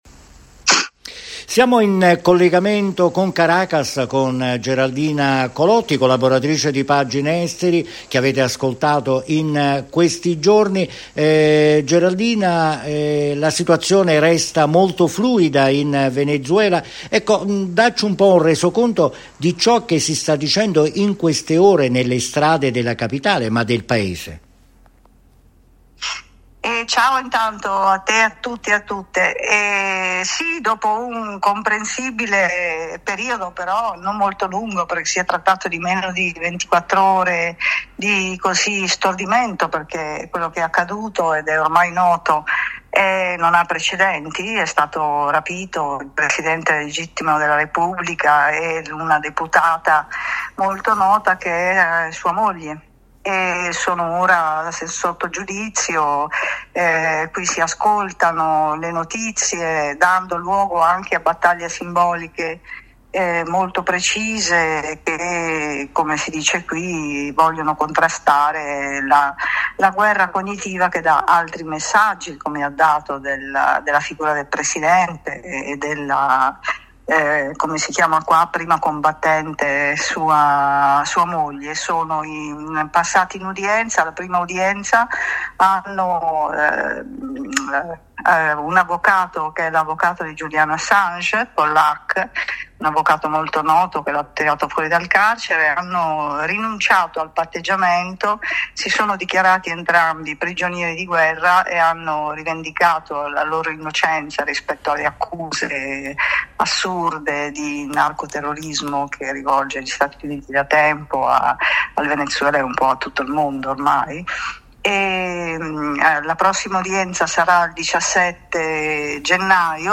Da Caracas ascoltiamo la corrispondenza